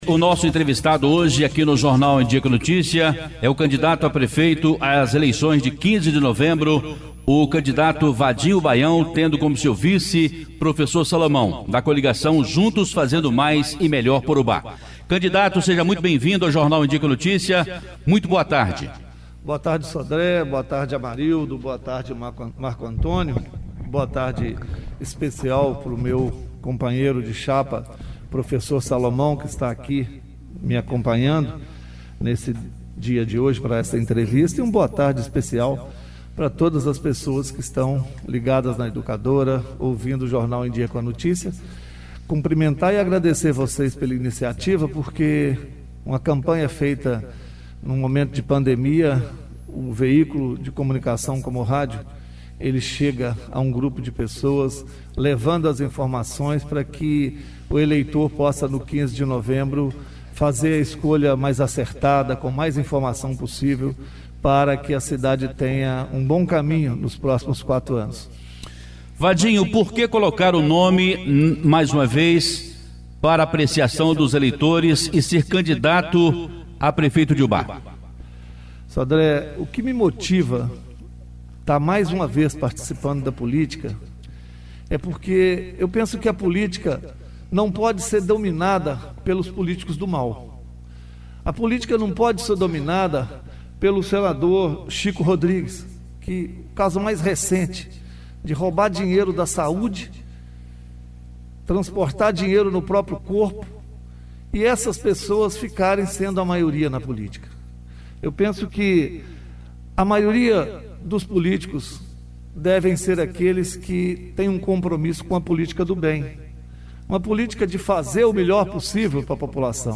Educadora na boca da urna!Entrevista às 12h30.
Entrevista exibida na Rádio Educadora AM/FM Ubá-MG